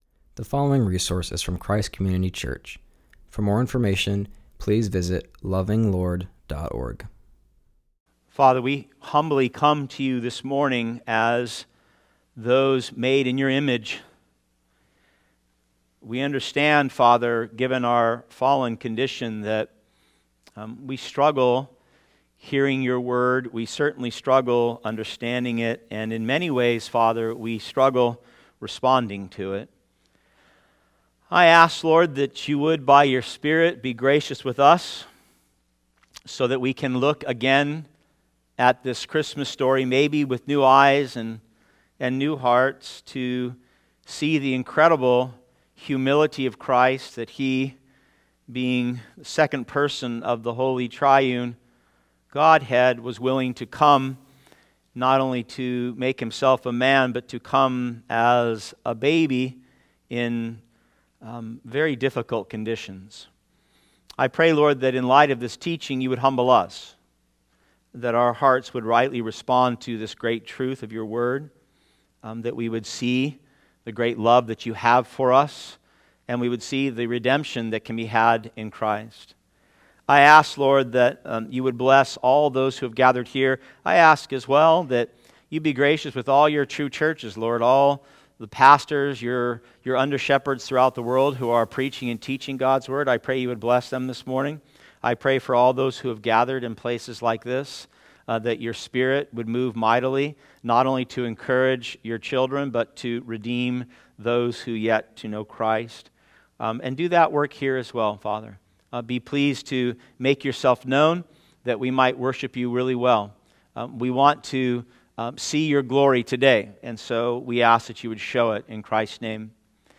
continues our series and preaches from Luke 2:1-20.